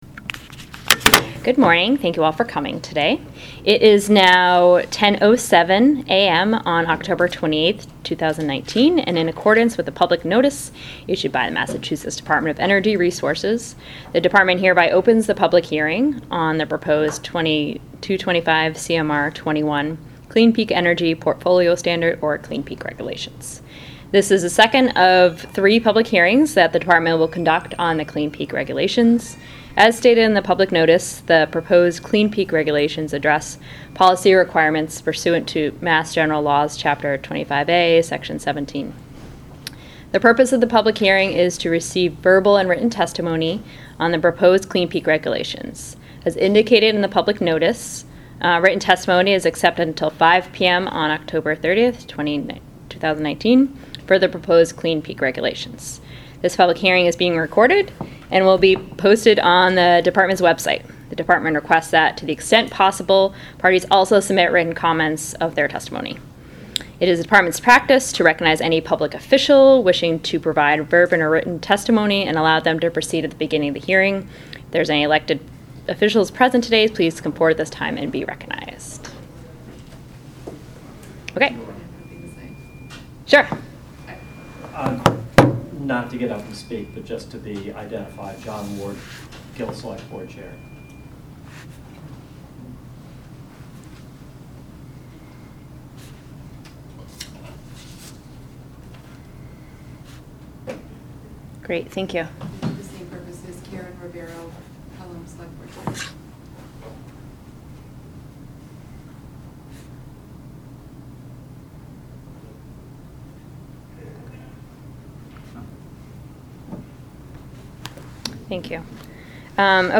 Open MP3 file, 70.83 MB, Clean Peak Standard Public Hearing 10.28 Audio Part 1 (MP3 70.83 MB) Last Updated: 2019-10-29 Language: English Contributing Organization Massachusetts Department of Energy Resources